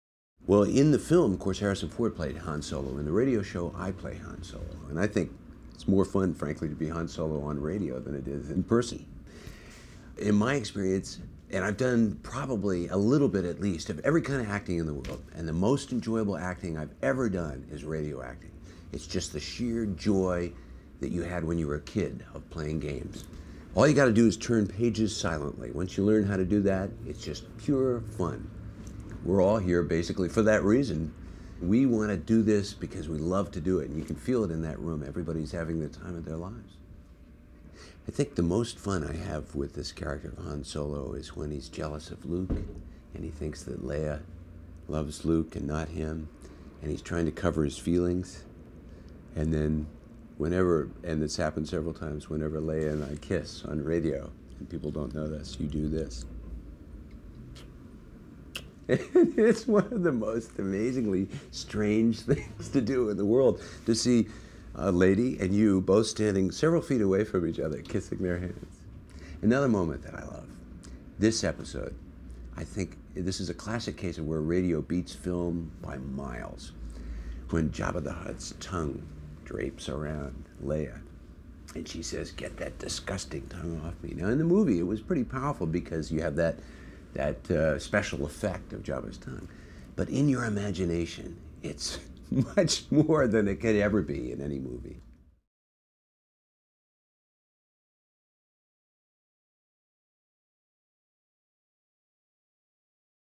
09 - Bonus Track - Comments from Perry King During the Recording Sessions.flac